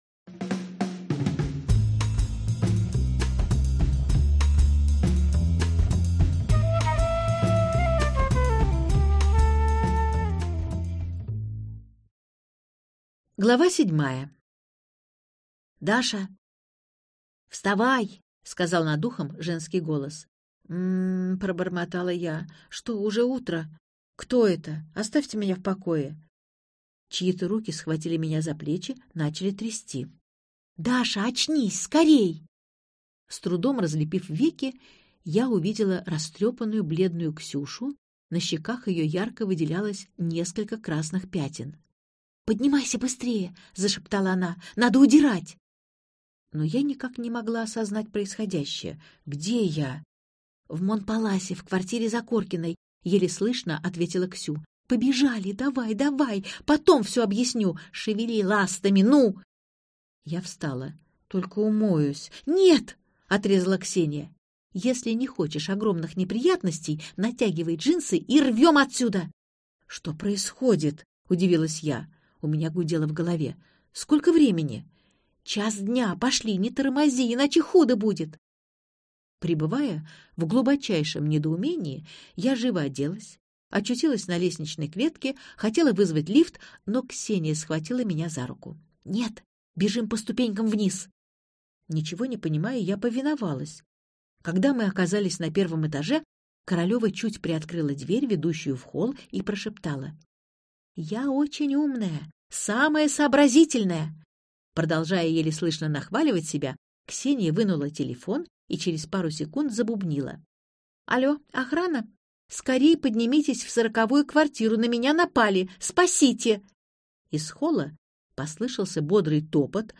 Аудиокнига Свидание под мантией - купить, скачать и слушать онлайн | КнигоПоиск